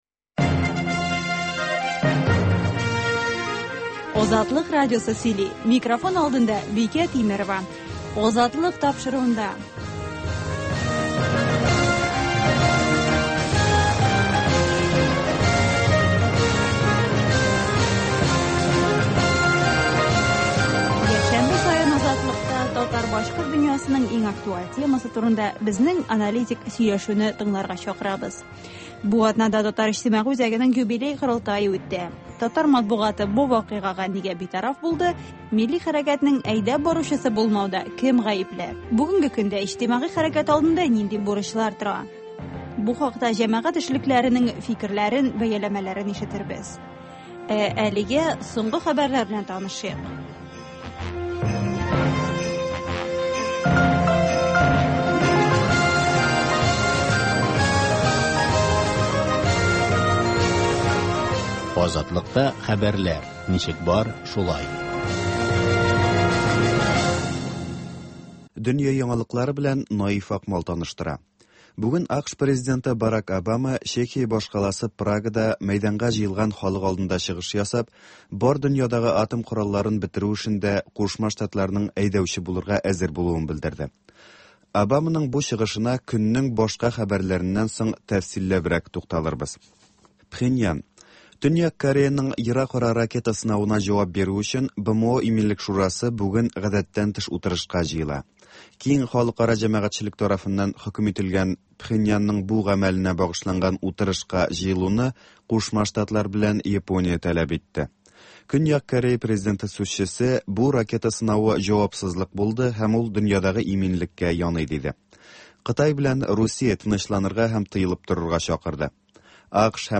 Азатлык узган атнага күз сала - соңгы хәбәрләр - түгәрәк өстәл сөйләшүе